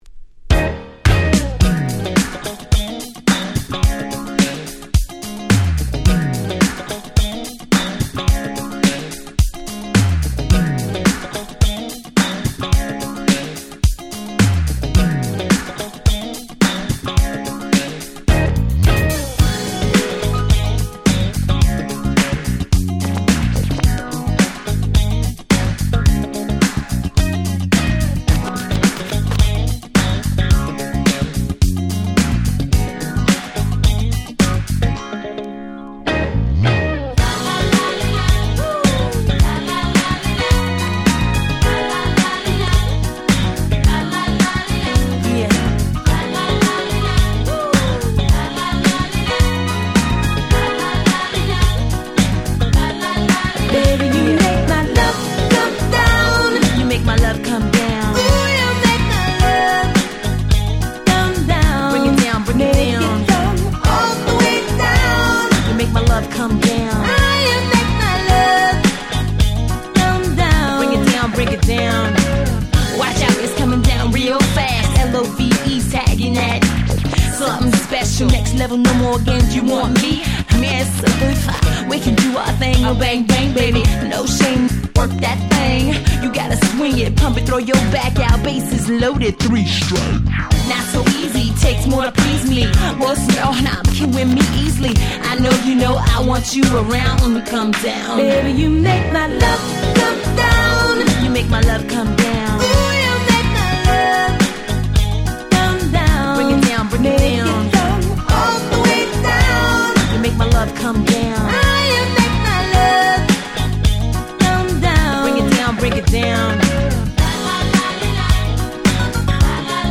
99' Nice Cover R&B !!
キャッチー系 歌ラップ 90's